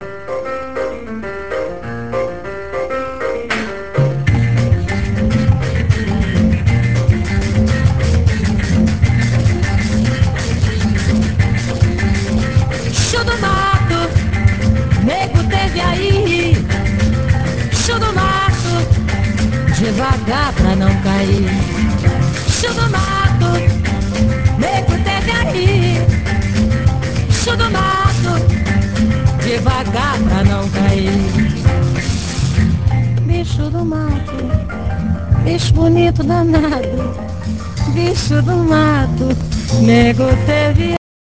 dans un style plus spontané